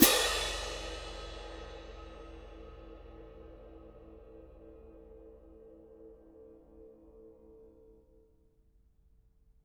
cymbal-crash1_mf_rr2.wav